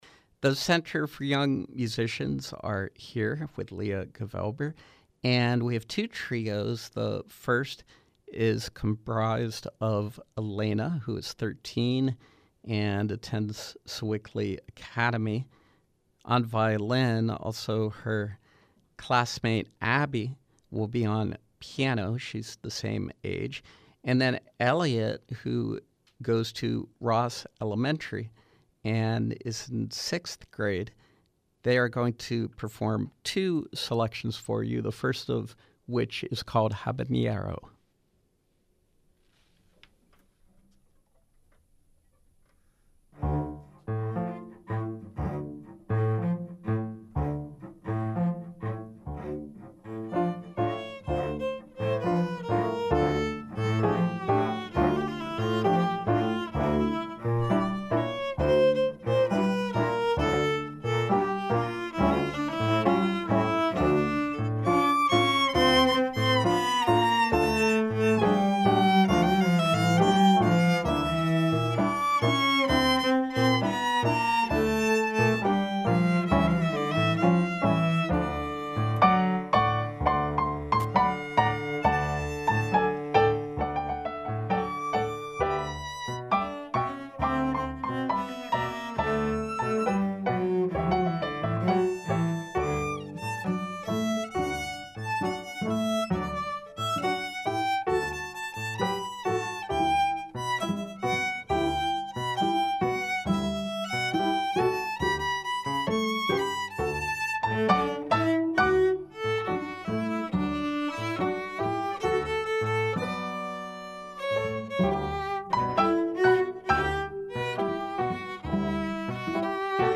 trio groups
violin
cello
piano